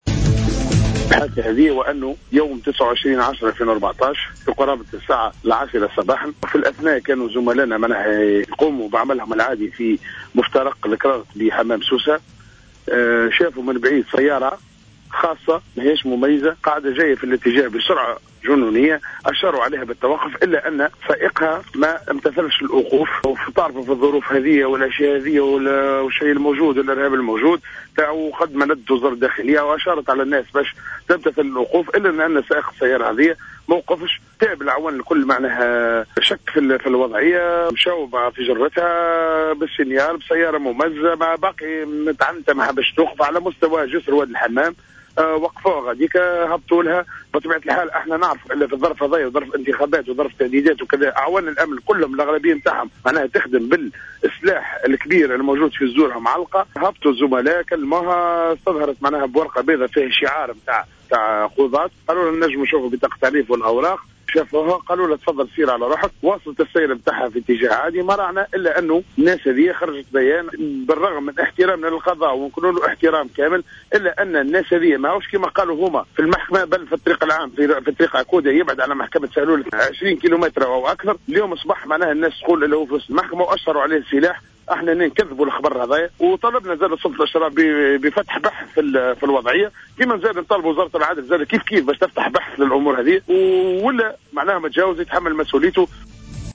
في اتصال هاتفي مع "جوهرة اف أم"